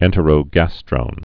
(ĕntə-rō-găstrōn)